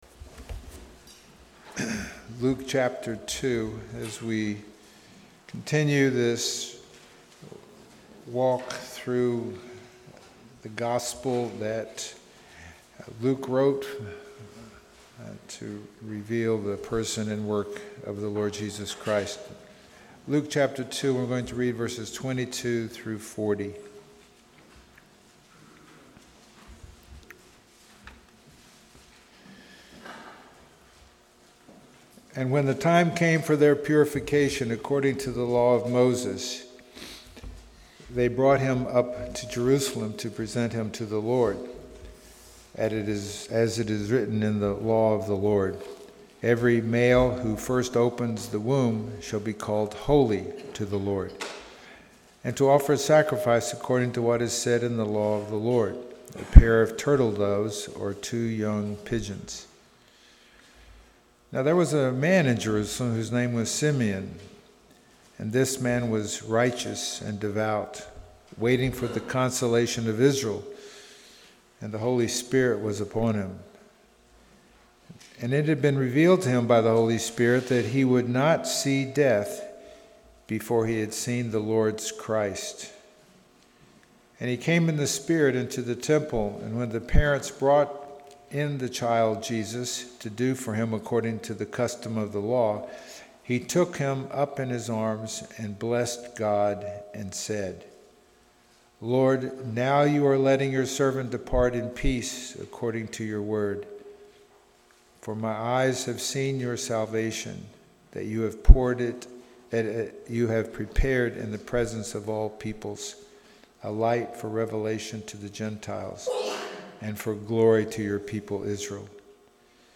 Luke Passage: Luke 2:22-40 Service Type: Sunday Morning « Stooping So Low